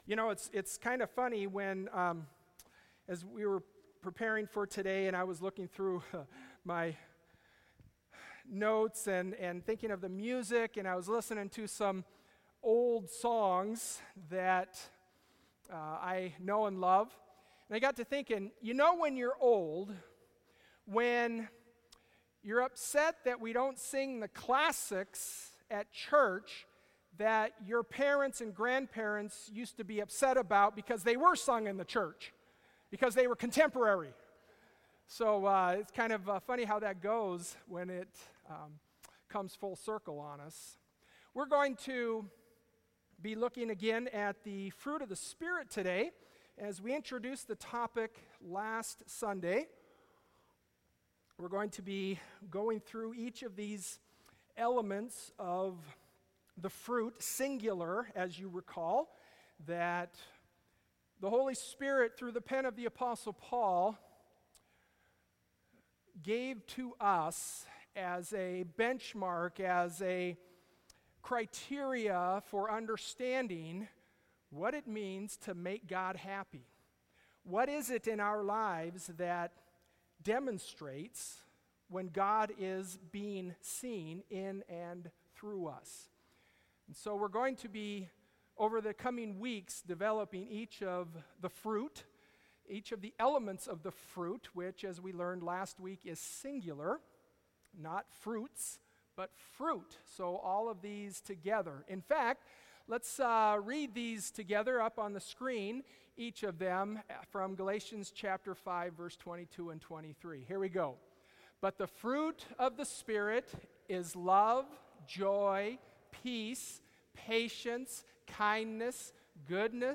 Bible Text: 1 Corinthians 13:4-8a | Preacher